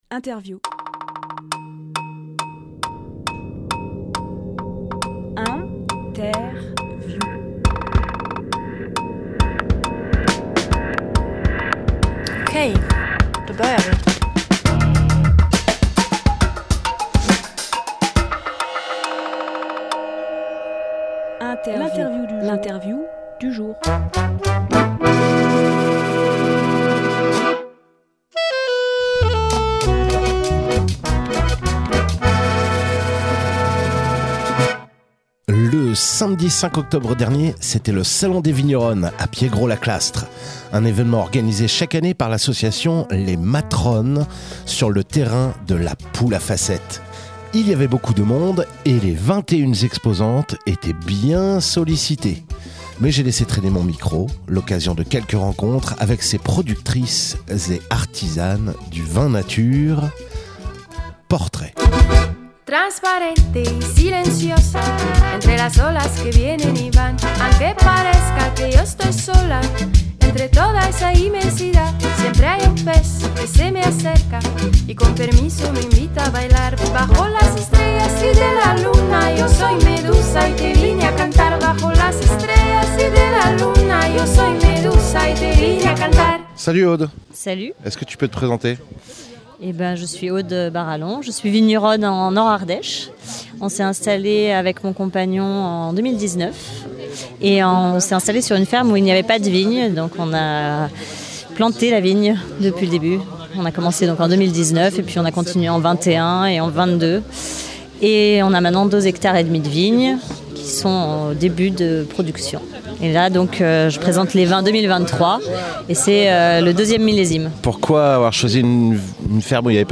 Emission - Interview Salon des Vigneronnes 2024
Lieu : Piègros-la-Clastre